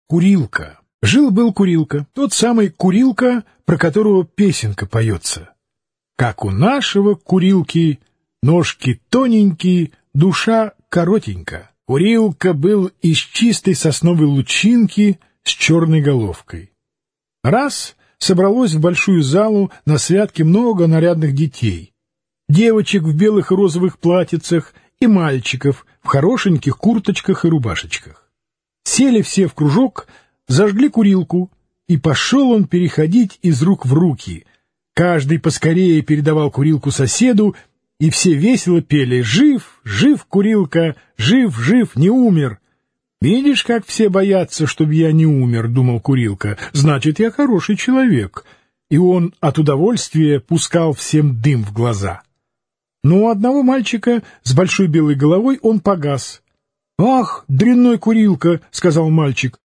Аудиокнига Сказки Кота-Мурлыки 2 | Библиотека аудиокниг